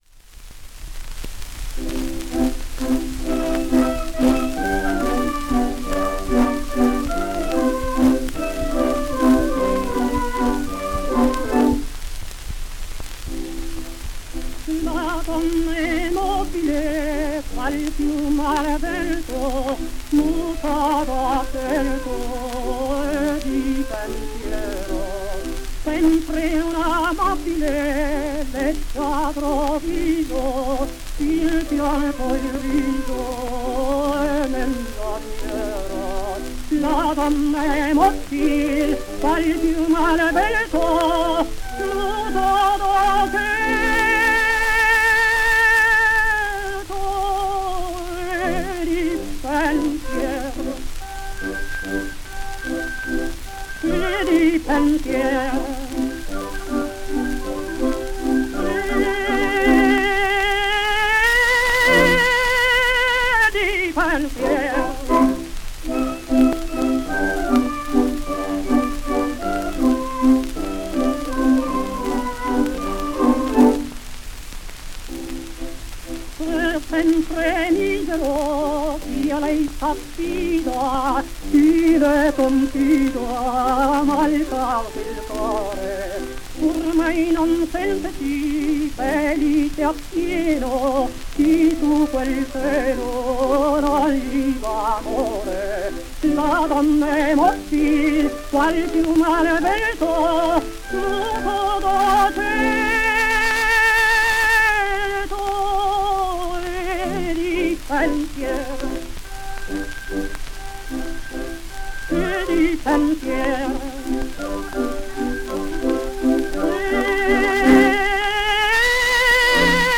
Beka/ERA,  Berlin, early 1911